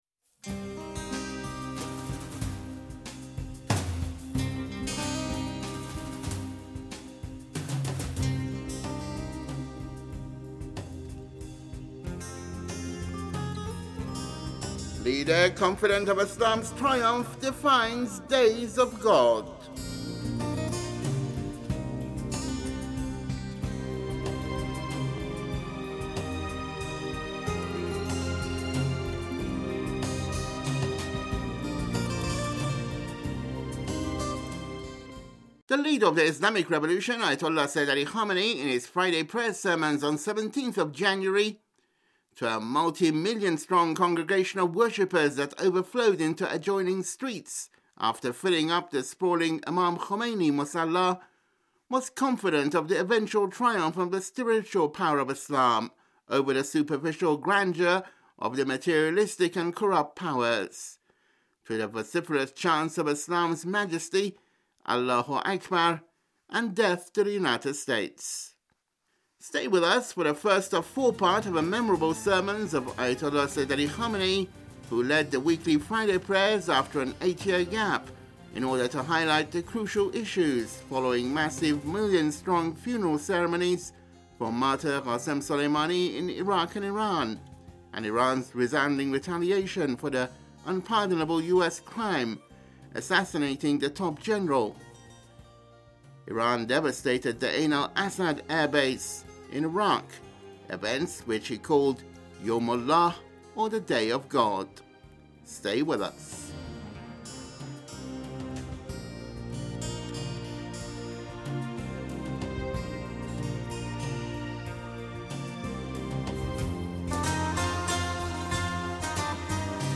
Here is the first of the four parts of the memorable sermons of Ayatollah Khamenei, who led the weekly Friday Prayer on January 17 after an 8-year gap in o...